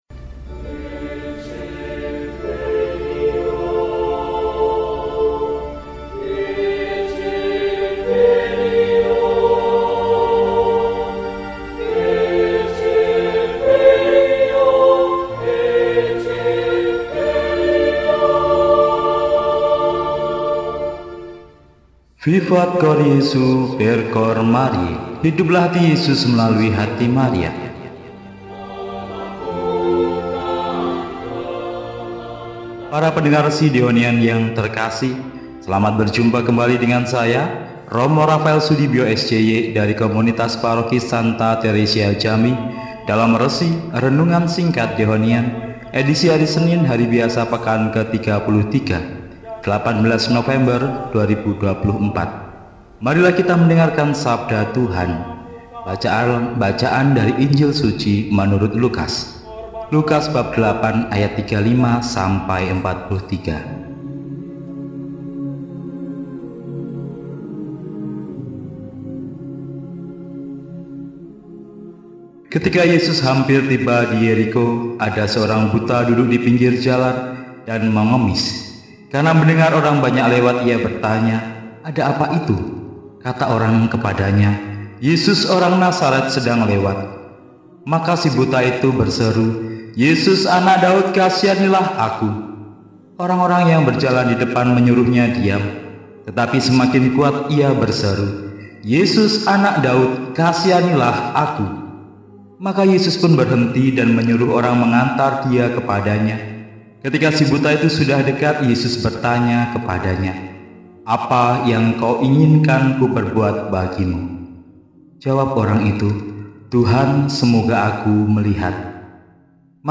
Senin, 18 November 2024 – Hari Biasa Pekan XXXIII – RESI (Renungan Singkat) DEHONIAN